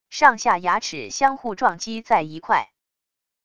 上下牙齿相互撞击在一块wav音频